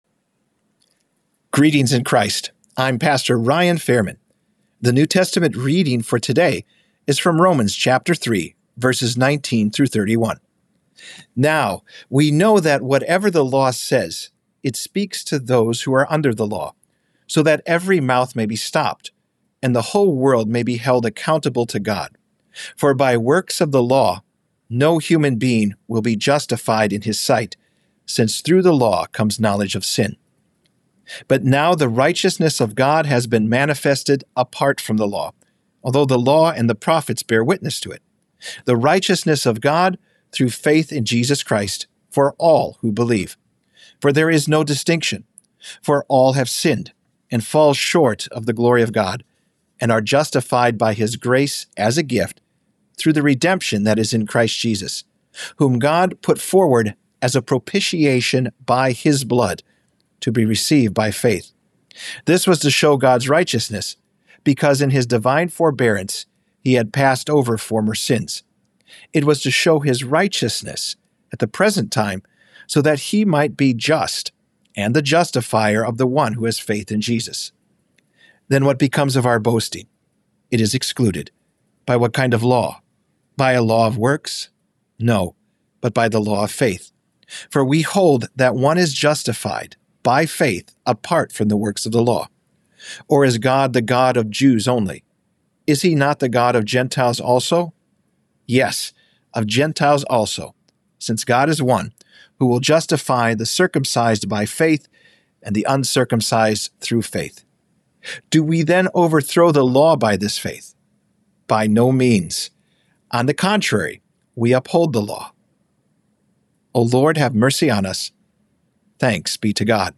Morning Prayer Sermonette: Romans 3:19-31
Hear a guest pastor give a short sermonette based on the day’s Daily Lectionary New Testament text during Morning and Evening Prayer.